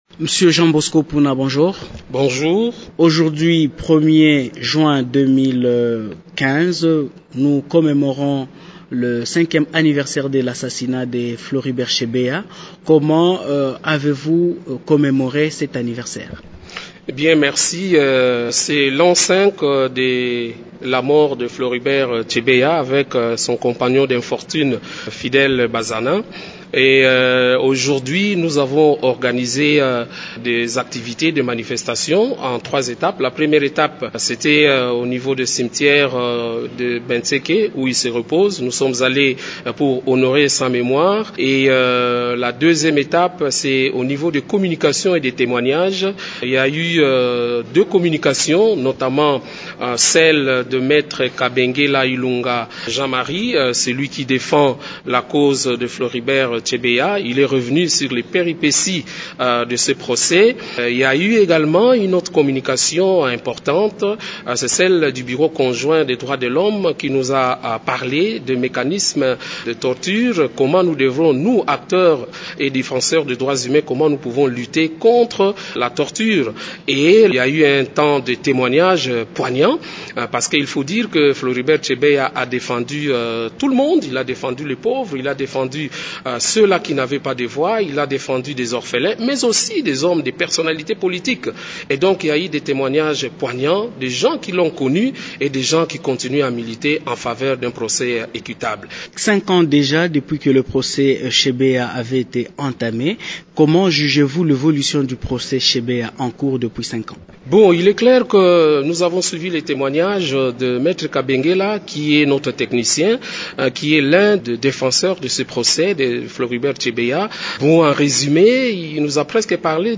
Il est interrogé par